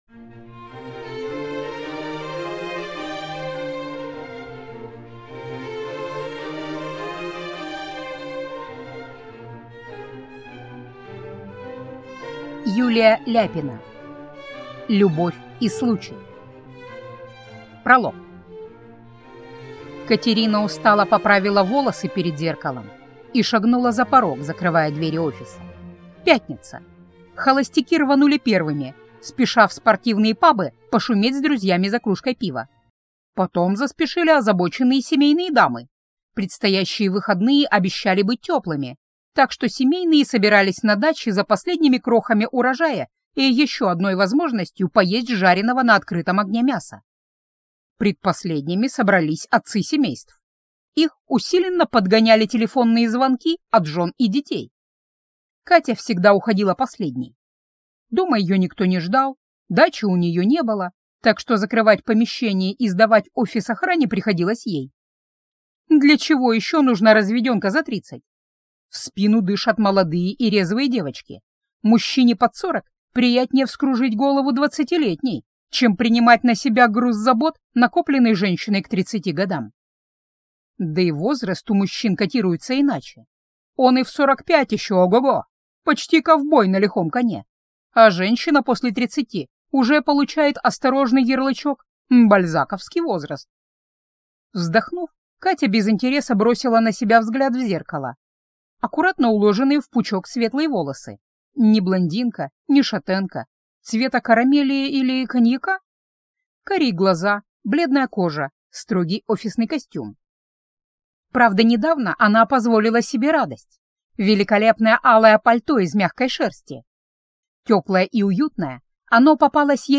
Аудиокнига Любовь и случай